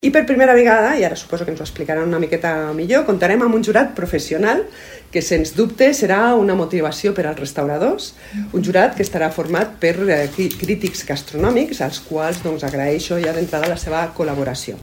Ells decidiran el nou premi al millor plat de la mostra, que s’afegeix al tradicional guardó de votació popular. N’ha donat més detalls, la cònsol major d’Ordino, Maria del Marc Coma.